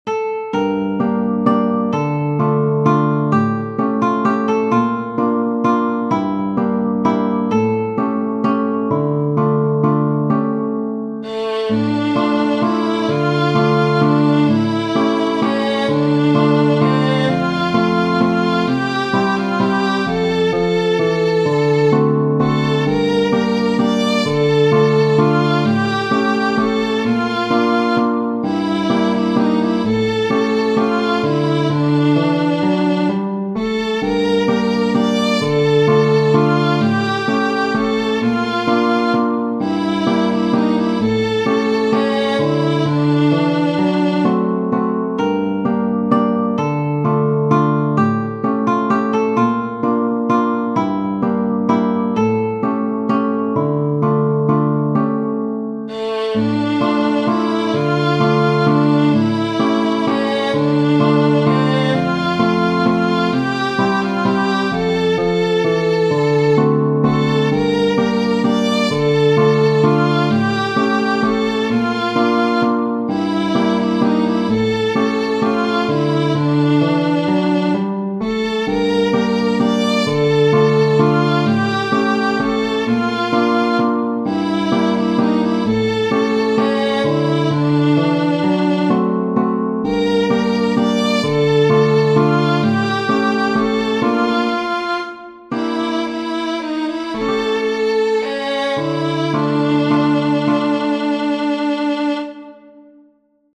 Tradizionale Genere: Folk Testo di anonimo Să-mi cânţi, cobzar, bătrân, ceva, Să-mi cânţi ce ştii mai bine, Că vin ţi-oi da, şi bani ţi-oi da, Şi haina de pe mine!